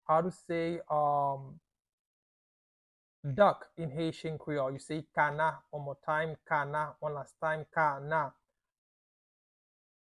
Pronunciation:
Listen to and watch “Kana” audio pronunciation in Haitian Creole by a native Haitian  in the video below:
How-to-say-Duck-in-Haitian-Creole-Kana-pronunciation-by-a-Haitian-teacher.mp3